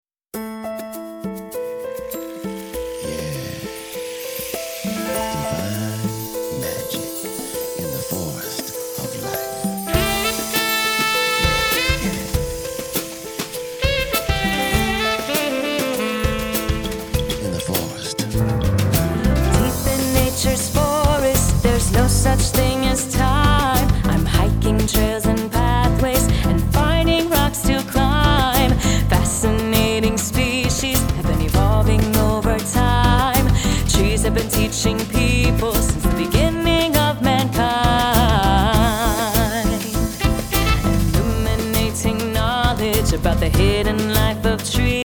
new concept in children's music